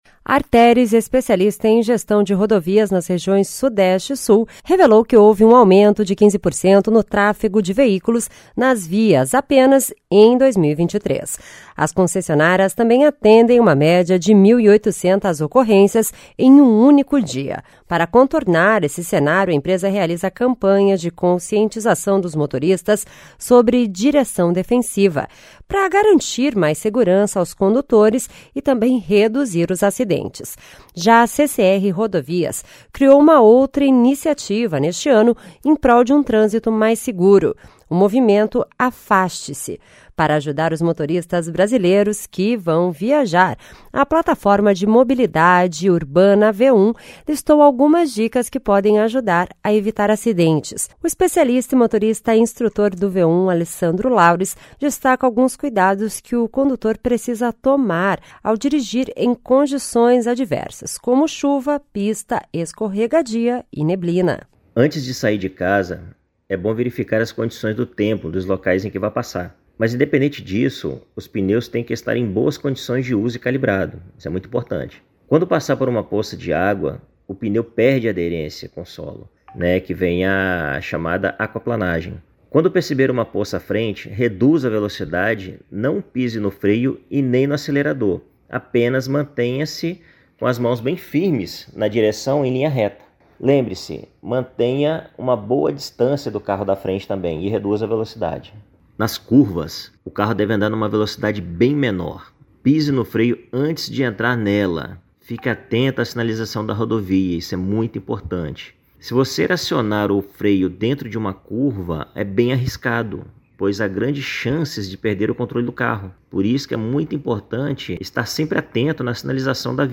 Especialista fala sobre direção defensiva.